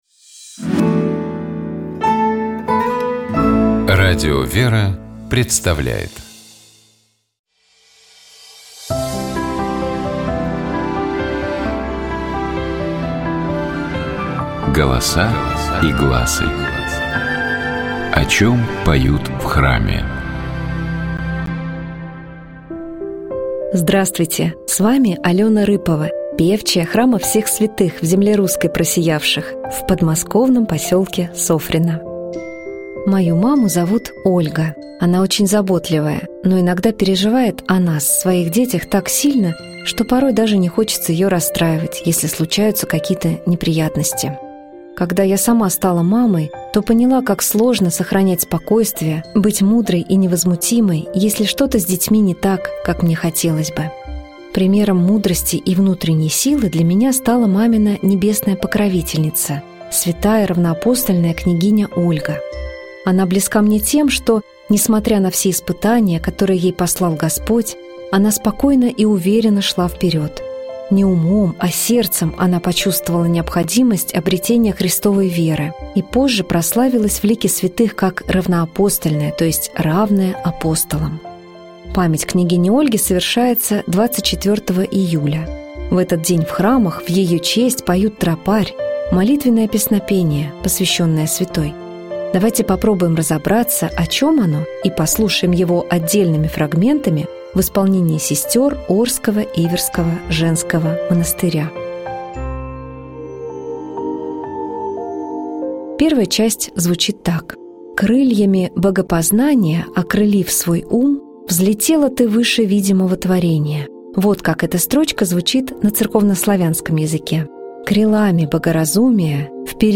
Давайте вместе послушаем тропарь святой равноапостольной княгине Ольге в исполнении хора Свято-Успенского Дуниловского женского монастыря.